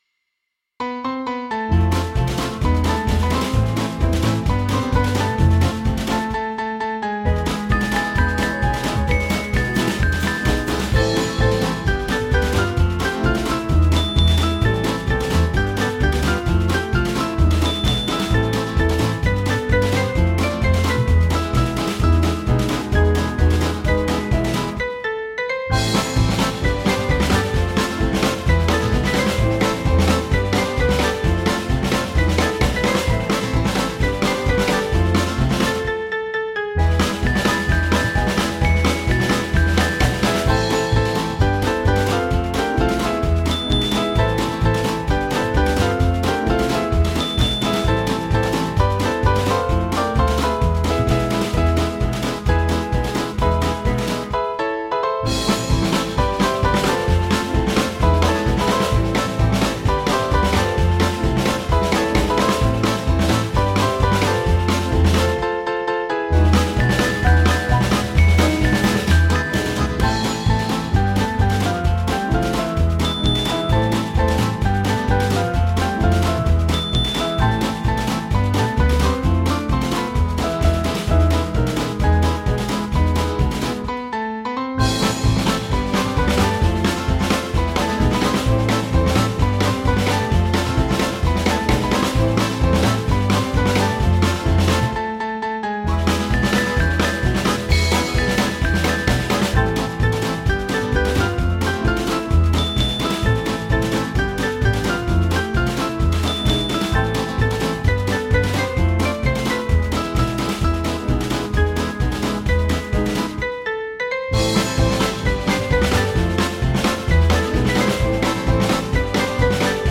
Small Band
(CM)   5/Am 467.3kb